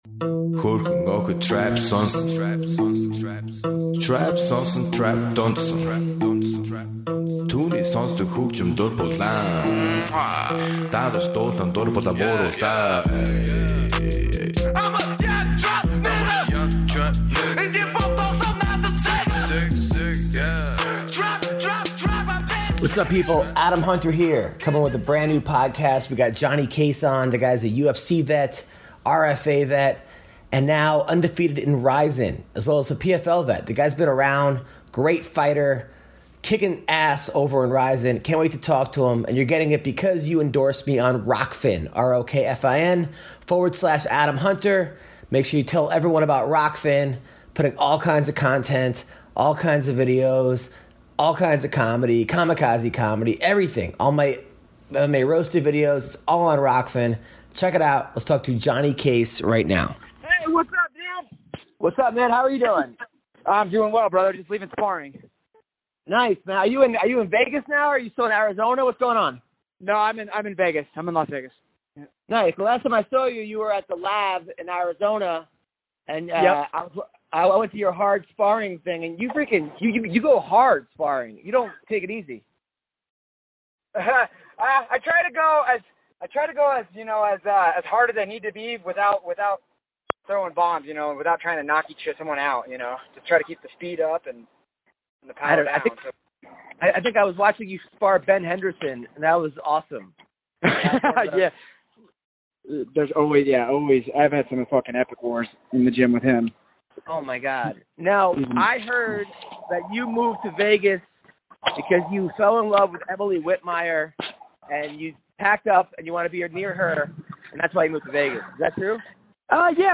takes a call from MMA fighter